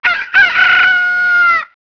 rooster0.wav